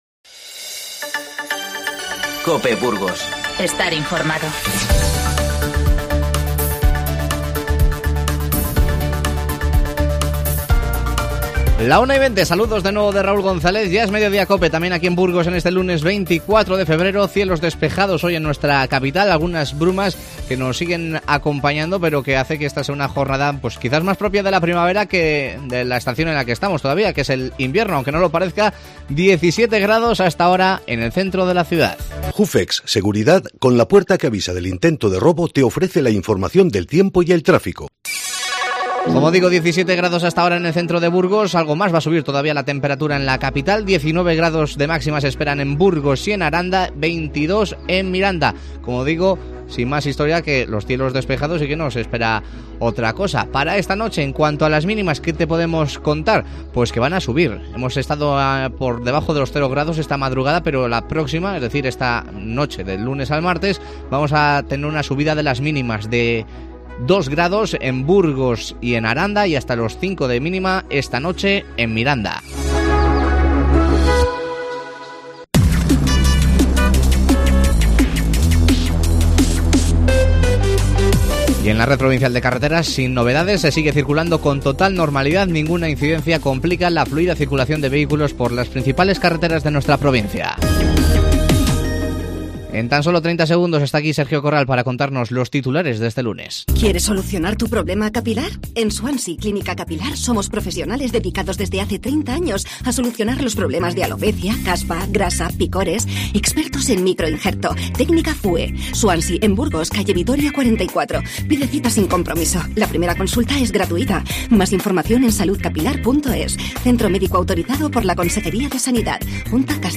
Te avanzamos las principales noticias del día y rescatamos algunos sonidos más de las chirigotas de Burgos a la par que te recordamos las citas que nos deja el Carnaval en la capital estos días.